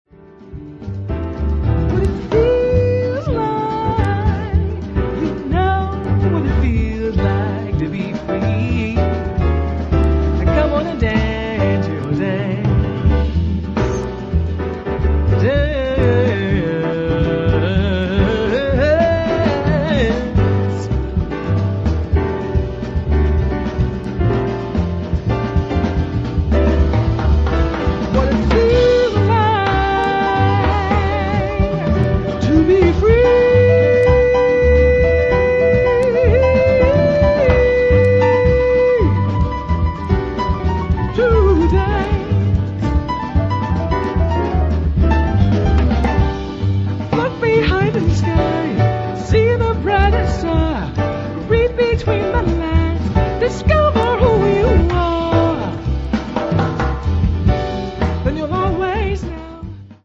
female jazz vocalists
she is accompanied by a great six piece jazz combo